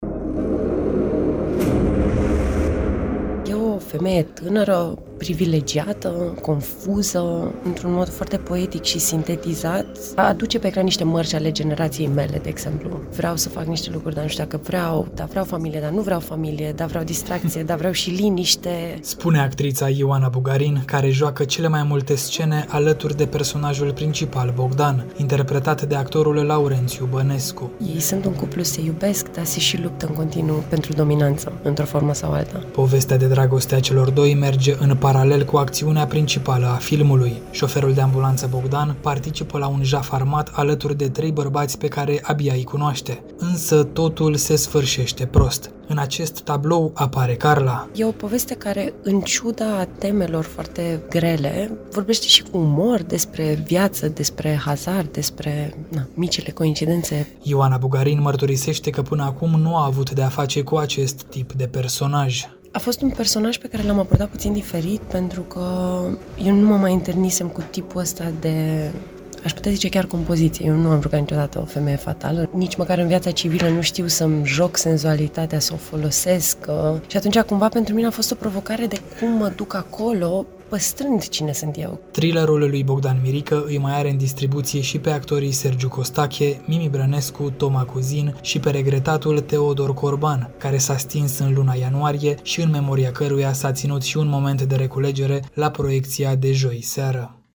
TIFF 2023 | INTERVIU